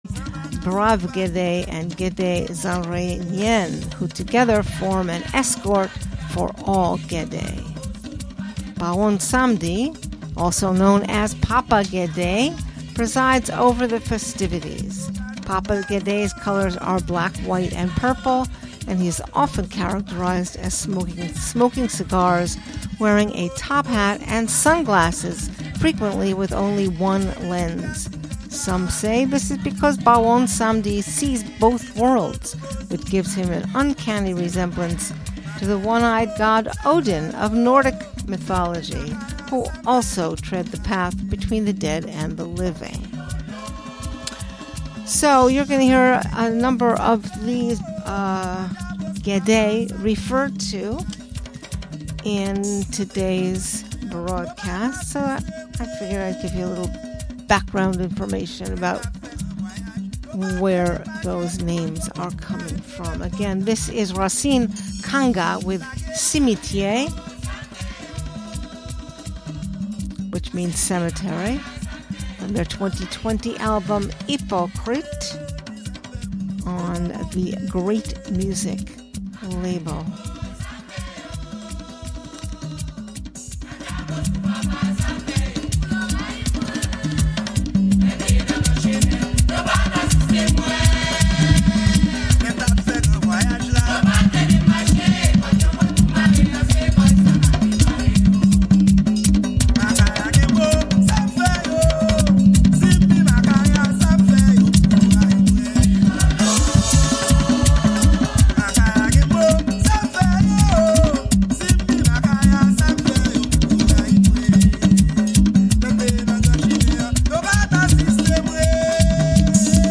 Featuring two hours of mizik rasin (roots music) driven by the Vodoun rhythms and chants that honor the Gede.
The show is broadcast live from Catskill, NY.